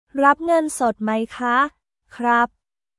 ラップ ンガンソット マイ カ／クラップ